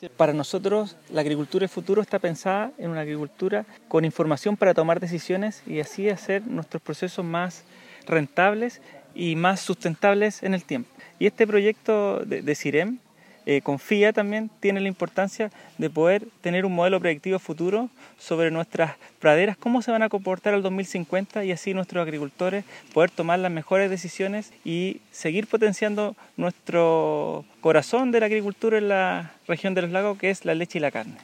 Cuña-Seremi-de-Agricultura.mp3